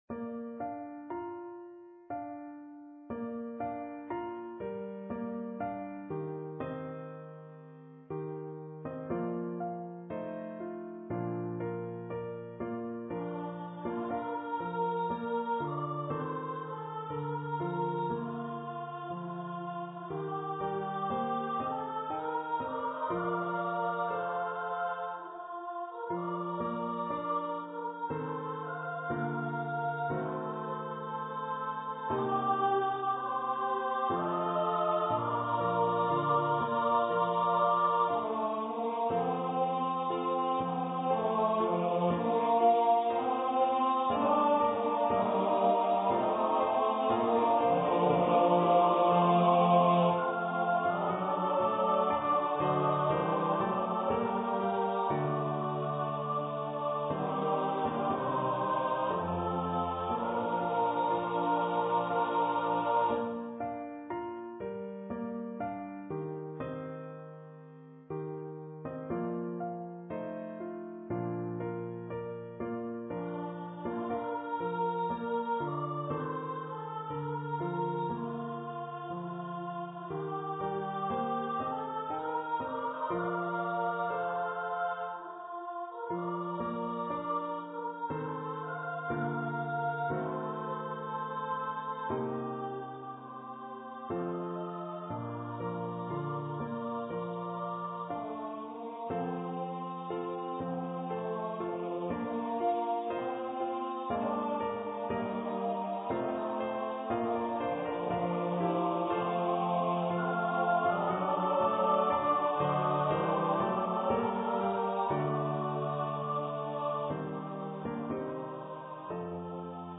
for mixed voice choir and piano
An eighteenth century Scottish melody
mixed voice choir (SATB) and piano